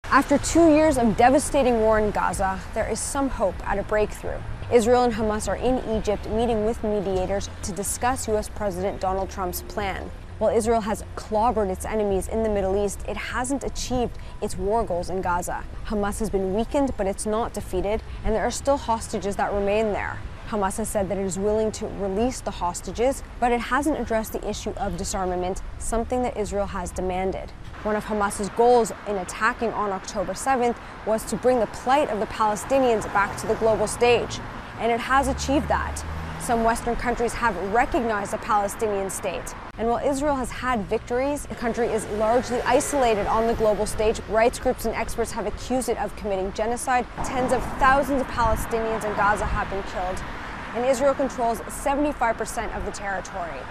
Reporting from Tel Aviv